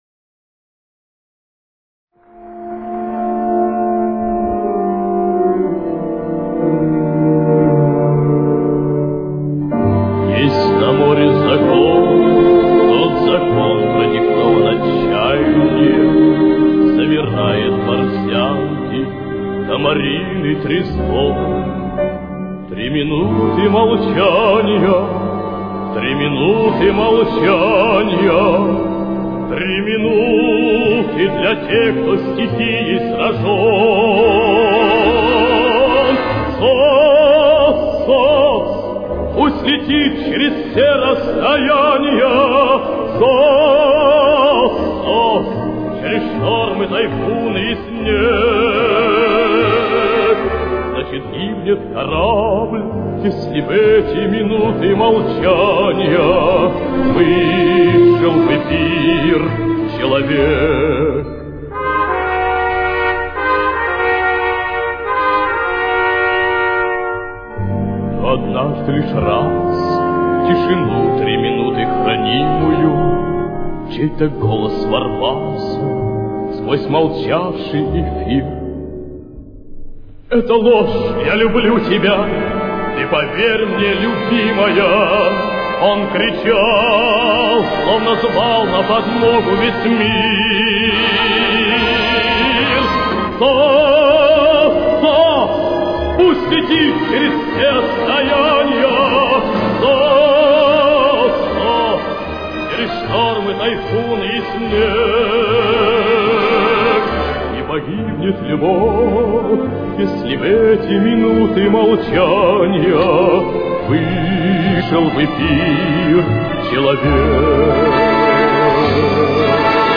эстрадный певец
с очень низким качеством (16 – 32 кБит/с)
Темп: 75.